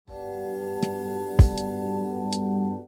Instrumentalmusik, PIANO, sms